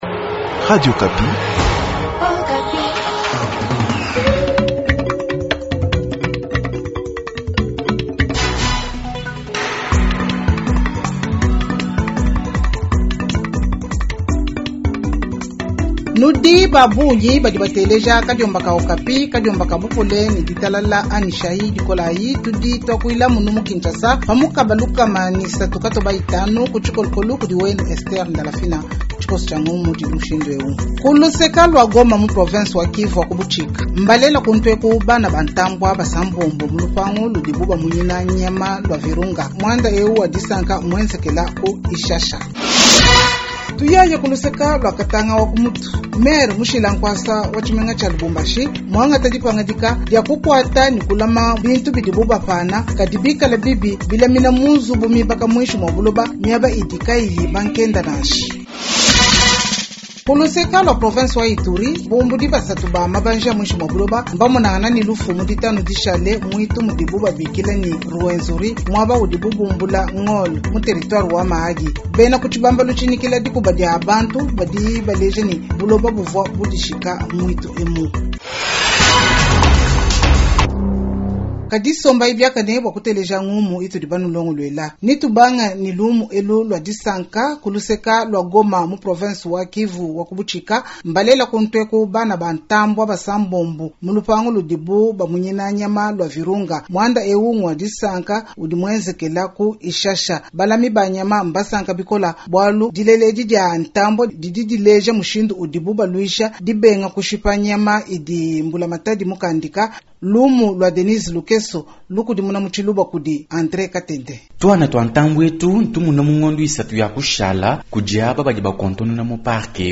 Journal tshiluba du 20 Octobre 2025